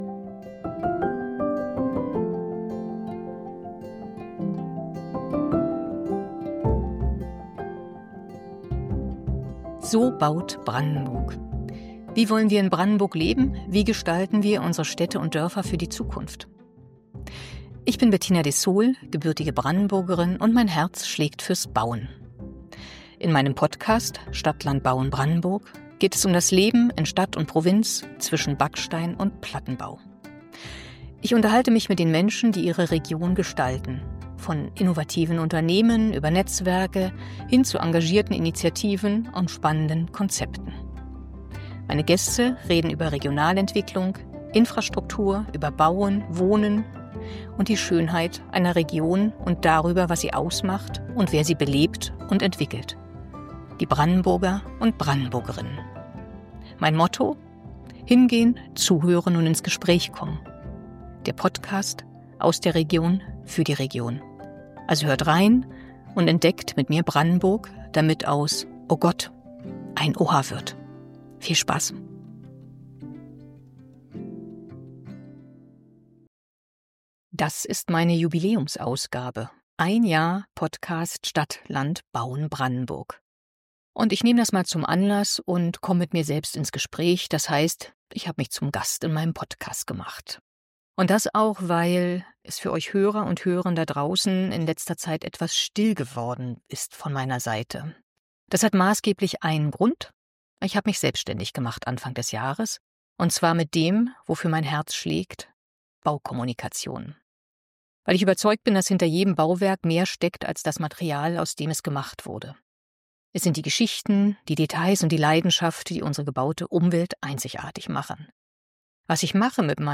In diesem Podcast geht es um das Leben in Stadt und Provinz, zwischen Backstein und Plattenbau. Ich unterhalte mich mit den Menschen, die die Region gestalten: Von innovativen Unternehmen über Netzwerke hin zu engagierten Initiativen und spannenden Konzepten. Meine Gäste reden über Regionalentwicklung, Infrastruktur, über Bauen, Wohnen und die Schönheit einer Region und darüber, was die Region ausmacht und wer sie belebt und entwicklt: die Brandenburger und Brandenburgerinnen.